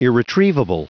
Prononciation du mot irretrievable en anglais (fichier audio)
Prononciation du mot : irretrievable